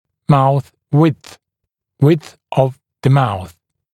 [mauθ wɪdθ] [wɪdθ əv mauθ][маус уидс] [уидс ов маус]ширина рта